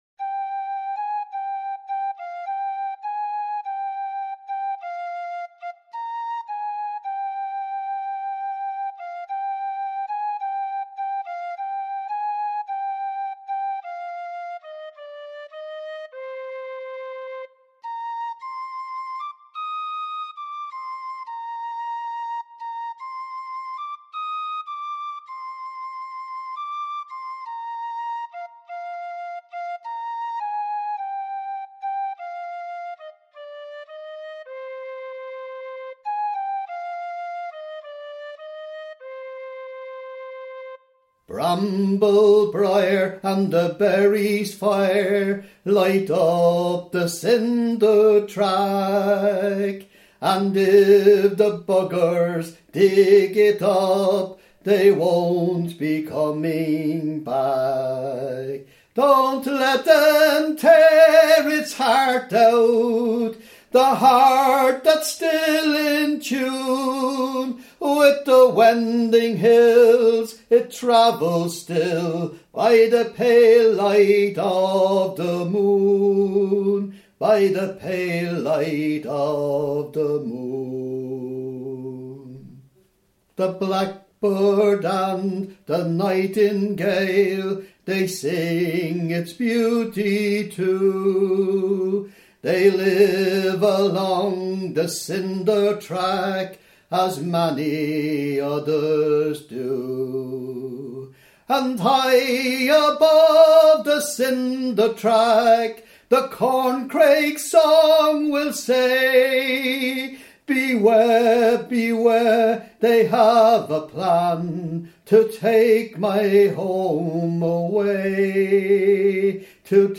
The Cinder Track — (Eb Major)